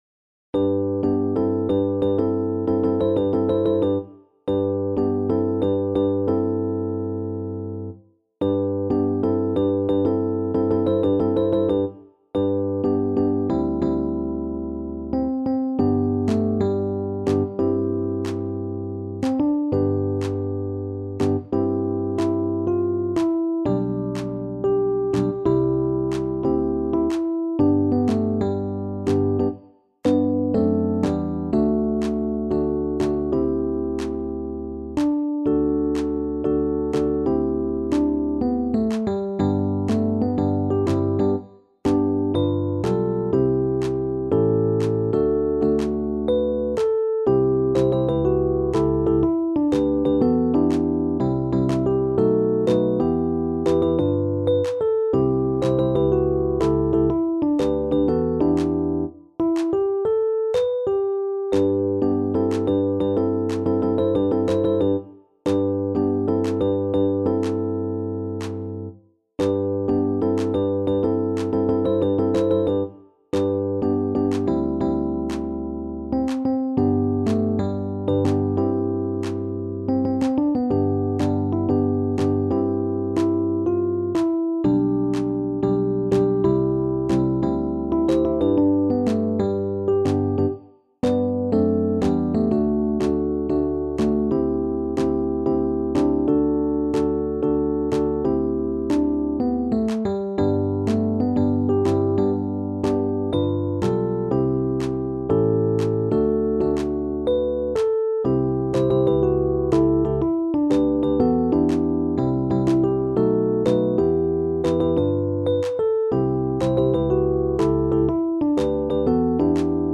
SATB met mezzo-solo | SSATB | SATTB | SSAB met mezzo-solo
Een verrassende, energieke gospel